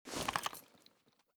mossberg_holster.ogg